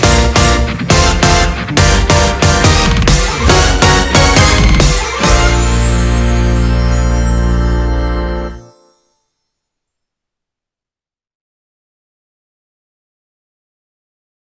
dt女性芸能人　変ake.wav